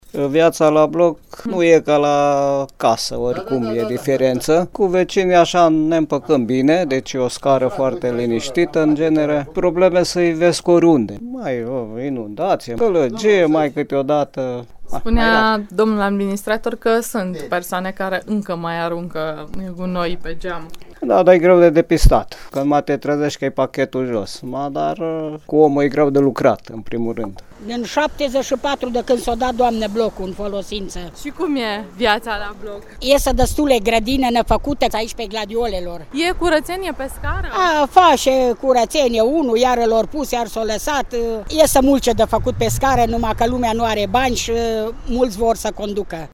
Câțiva cetățeni subliniază că viața la bloc nu e ca la casă și că probleme sunt oriunde: inundații, gălăgie etc. În ceea ce privește gunoaiele aruncate pe geam, locatarii indisciplinați nu prea sunt depistați, astfel încât să fie amendați.
vox-viata-la-bloc.mp3